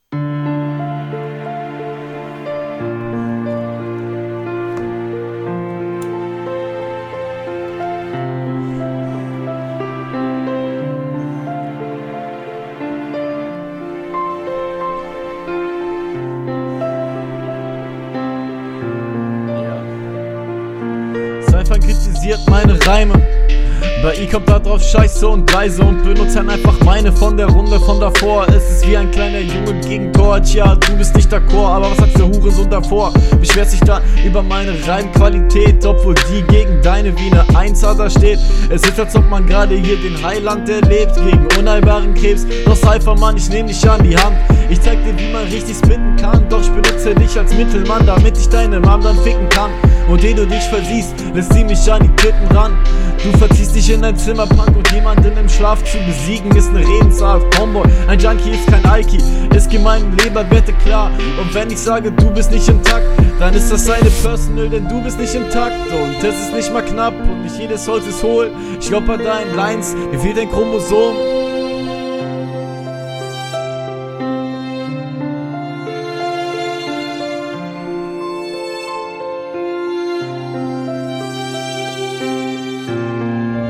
5 Sekunden reingehört, viel besser als der Gegner, Flowlich und von der Stimmfarbe auch.